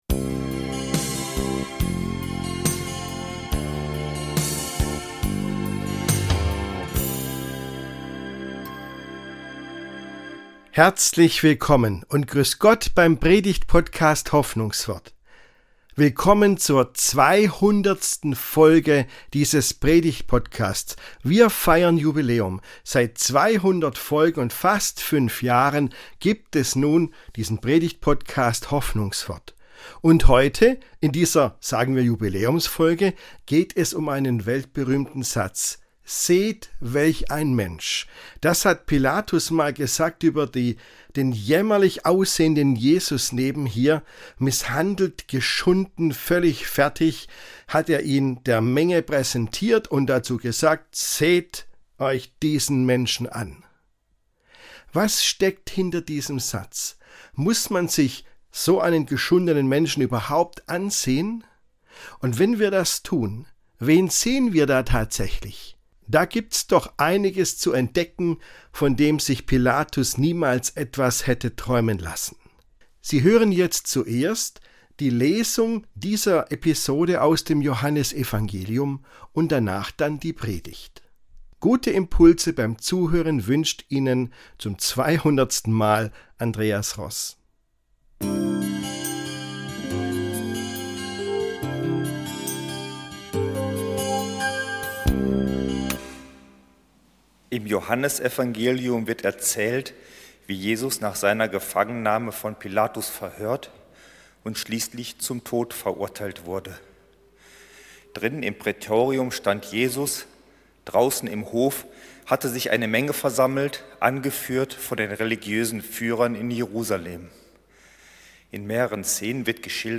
(Folge 200) Im Mittelpunkt dieser Predigt steht ein Satz, den Pontius Pilatus über Jesus gesprochen hat: “Seht, welch ein Mensch!” Durch alle Generationen hindurch hat dieser Satz und die dahinterstehende Szene Menschen bewegt.